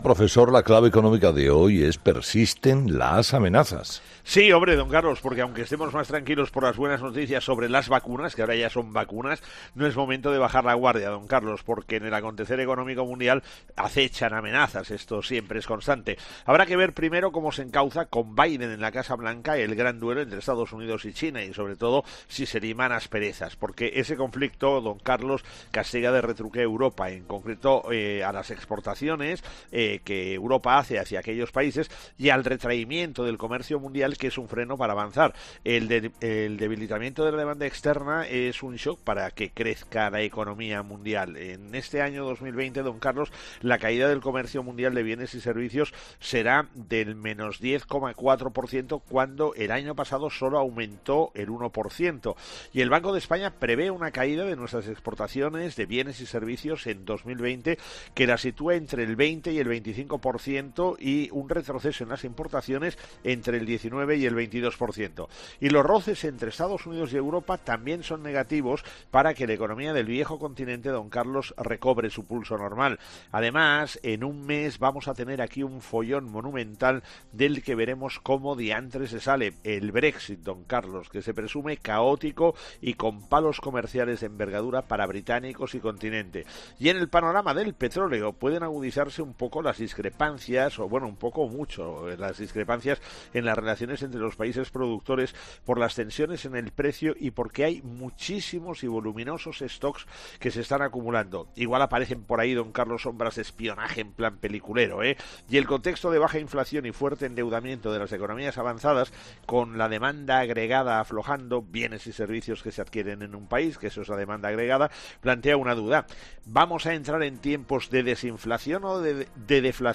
El profesor José María Gay de Liébana analiza en 'Herrera en COPE’ las claves económicas del día.